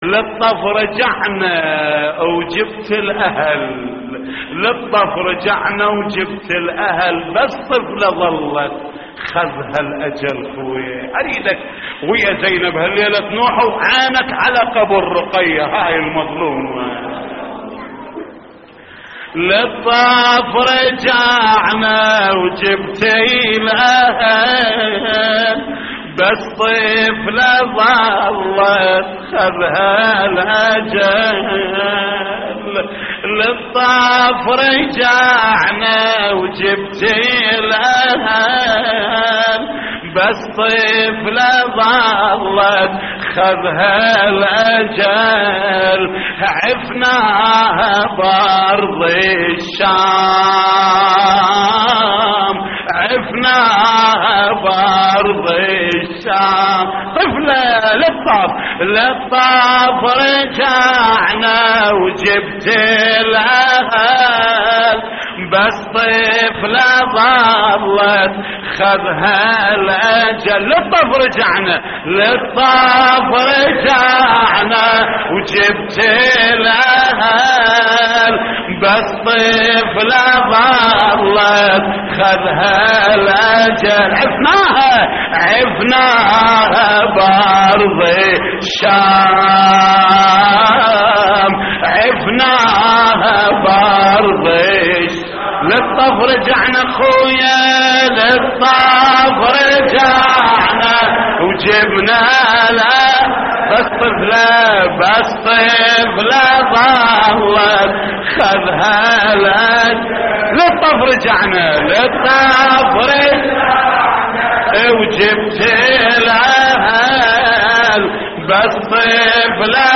تحميل : للطف رجعنا وجبتي الأهل بس طفلة ظلت / الرادود جليل الكربلائي / اللطميات الحسينية / موقع يا حسين